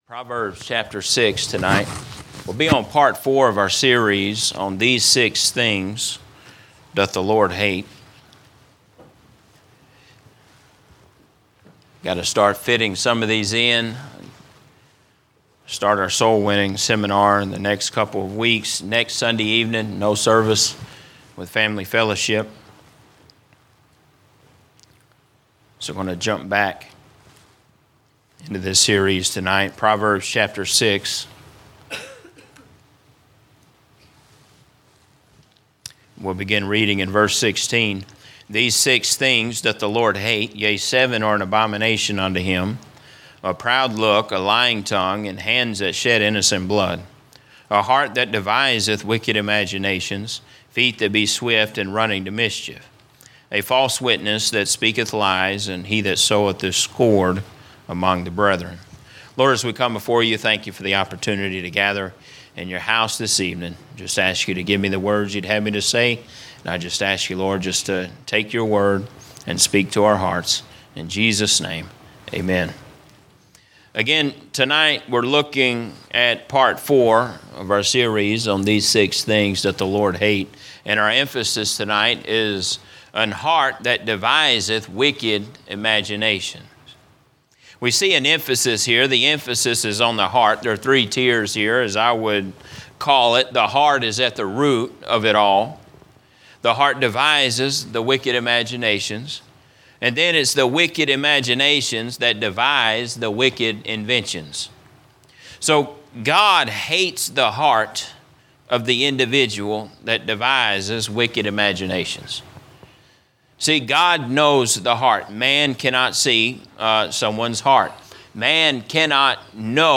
Sermons - Emmanuel Baptist Church